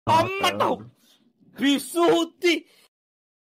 pissu hutti Meme Sound Effect